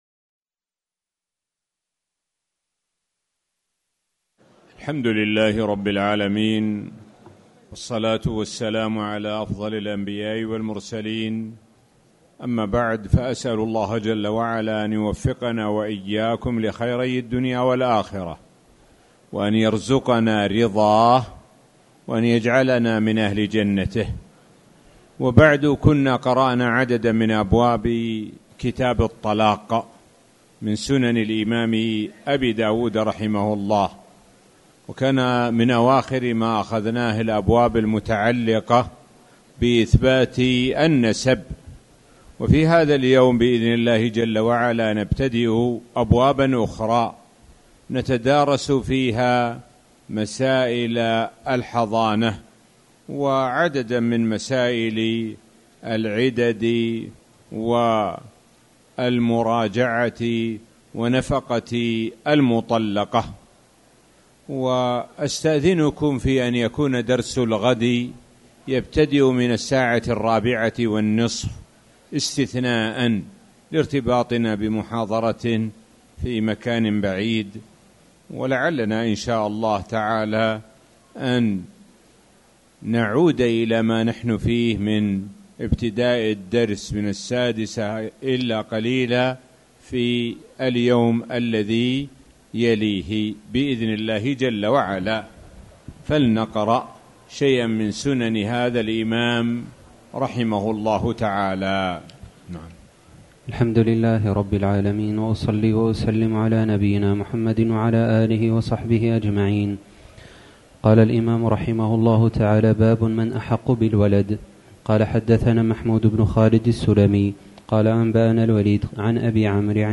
تاريخ النشر ١٤ ذو القعدة ١٤٣٨ هـ المكان: المسجد الحرام الشيخ: معالي الشيخ د. سعد بن ناصر الشثري معالي الشيخ د. سعد بن ناصر الشثري كتاب الطلاق